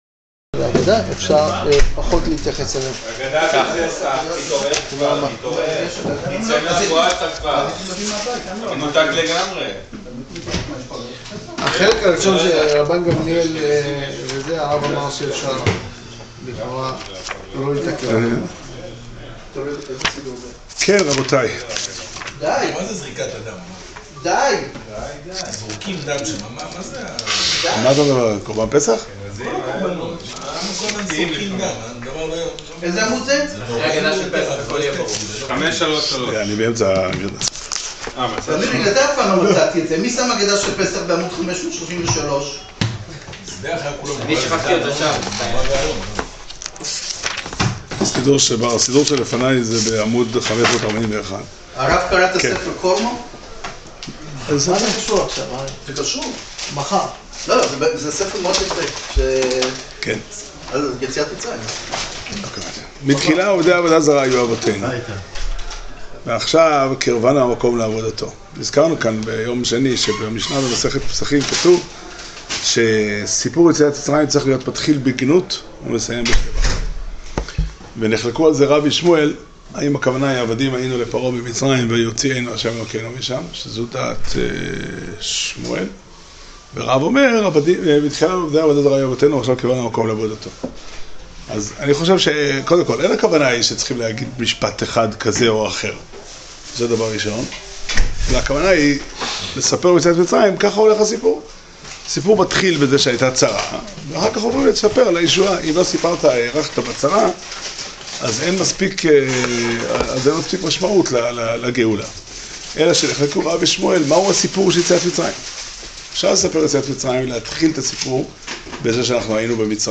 שיעור שנמסר בבית המדרש 'פתחי עולם' בתאריך כ' אדר ב' תשע"ט